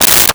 Plastic Zipper Short
Plastic Zipper Short.wav